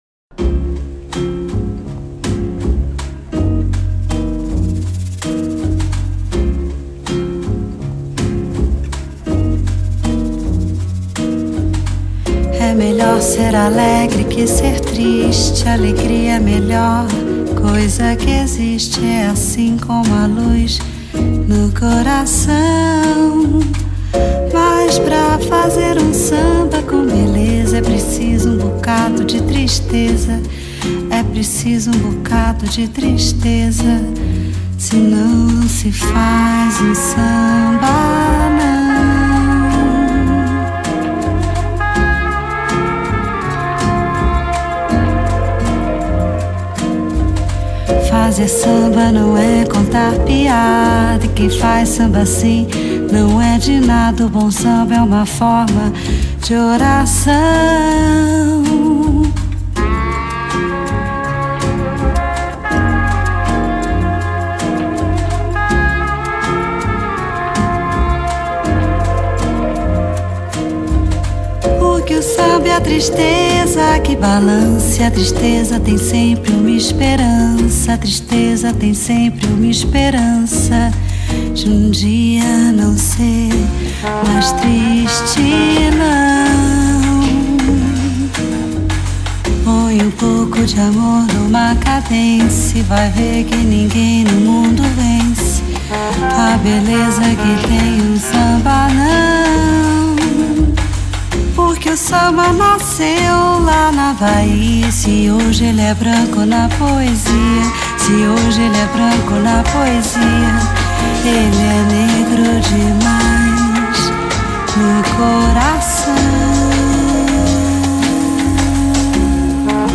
■Ladies' Jazz■